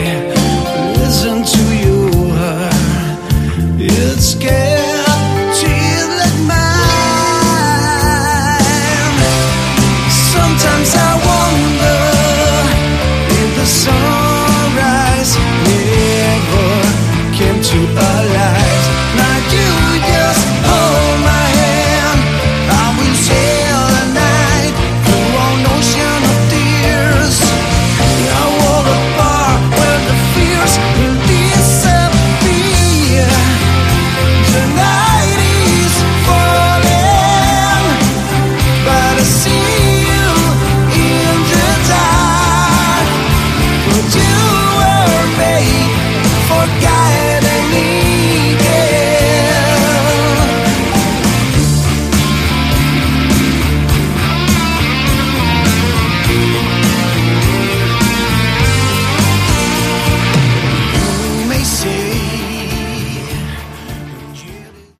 Category: Melodic Rock
Vocals
Guitar
Keyboards
Drums
Bass